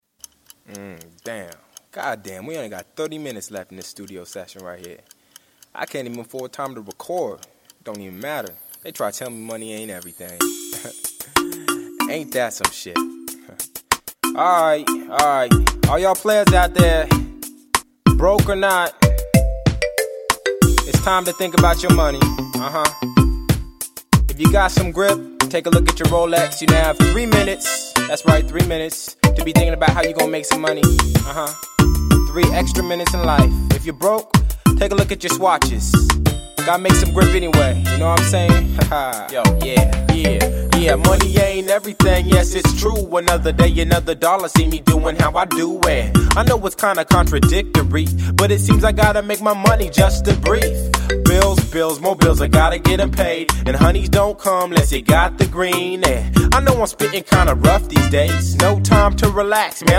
Genre: Rap & Hip-Hop.